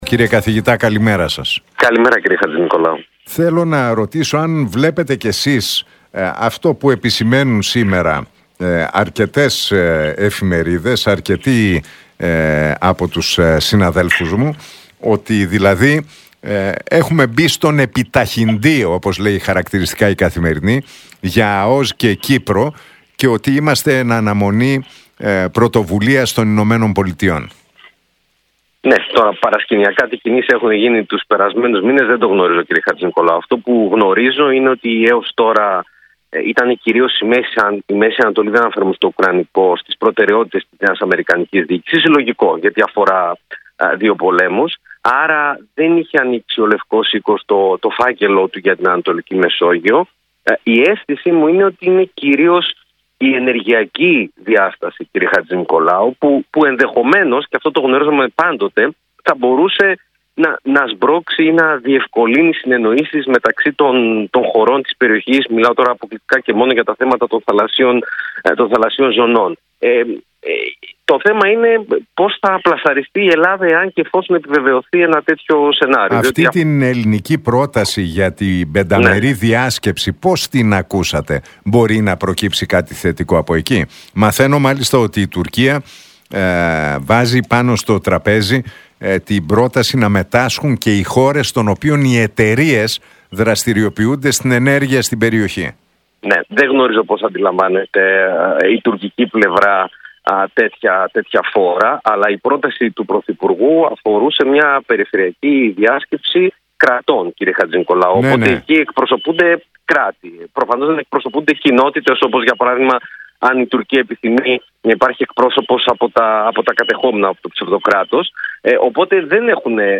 από την συχνότητα του Realfm 97,8.